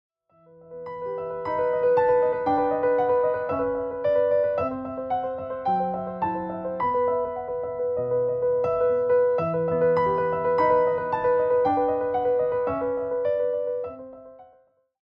carry gentle rhythmic energy